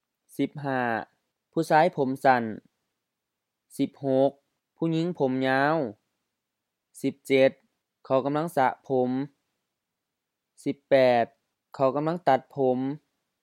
พุซาย phu-sa:i H-HR ผู้ชาย man, male
พุหญิง phu-ɲiŋ H-M ผู้หญิง woman, female
ญาว ɲa:o HR ยาว long